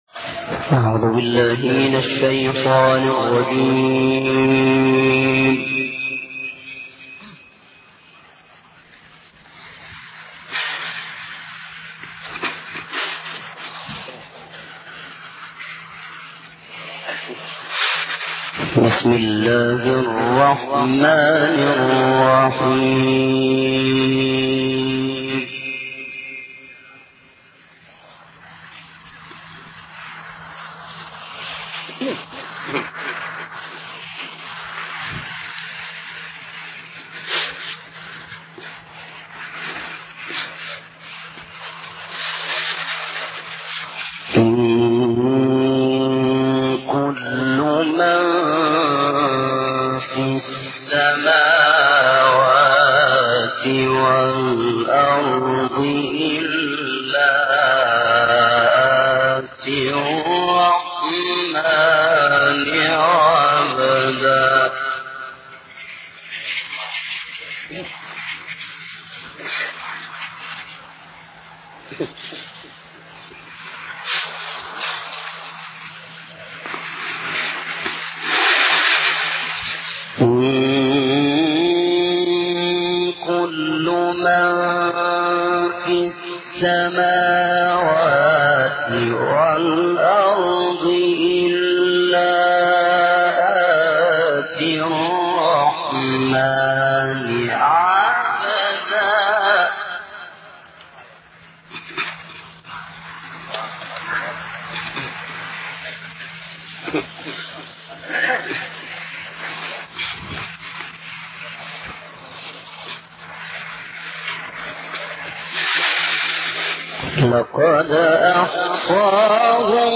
Quran recitations